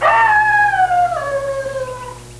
wolf.wav